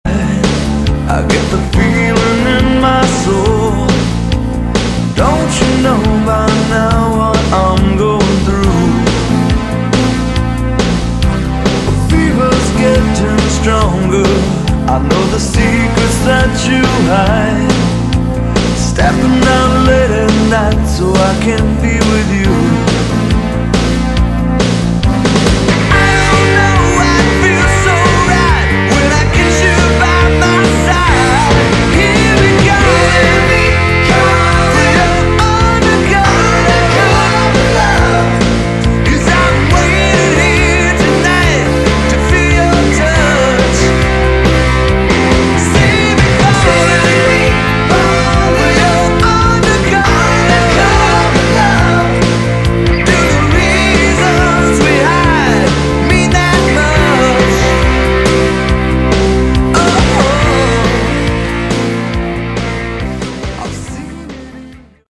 Slower Version